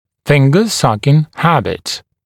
[‘fɪŋgə-‘sʌkɪŋ ‘hæbɪt][‘фингэ-‘сакин ‘хэбит]привычка сосания пальца (исключая большой палец)